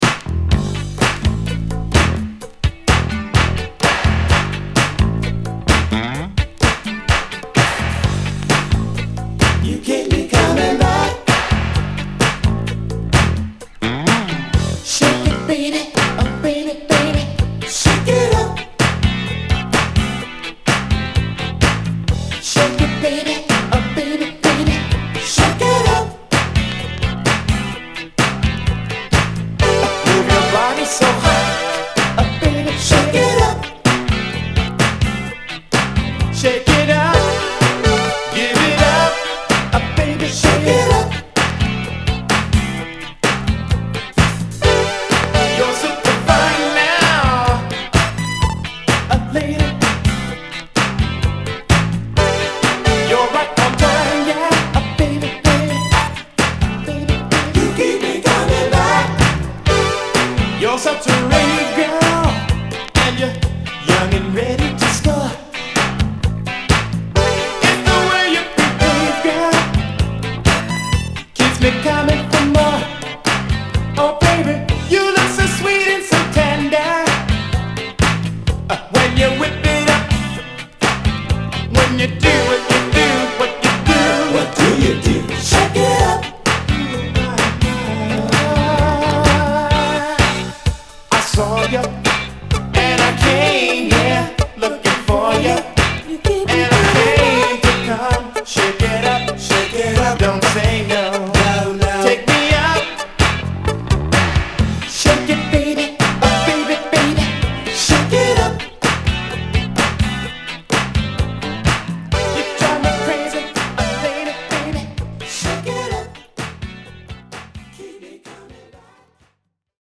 ホーム DISCO 80's 12' S